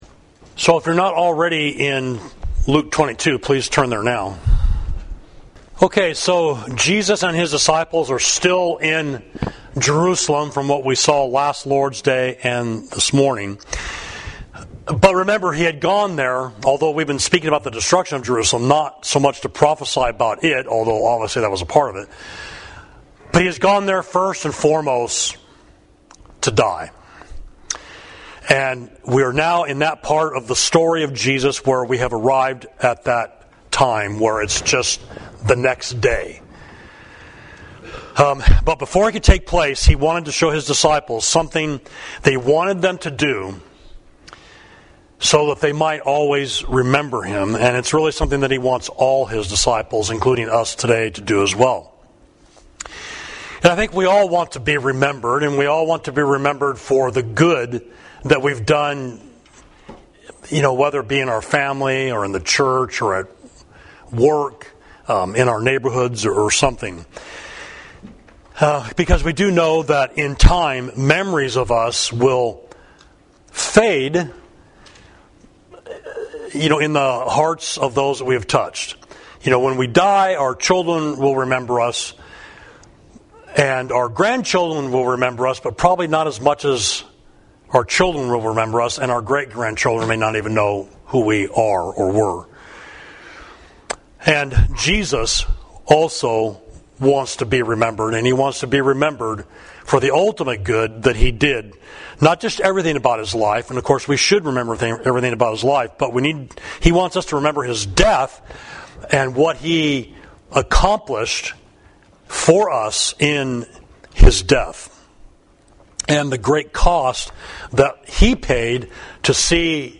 Sermon: The Lord’s Supper, Luke 22.1–23